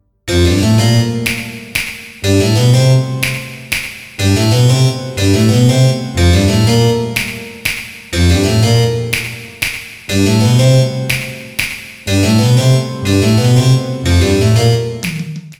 саундтреки
без слов
инструментальные
орган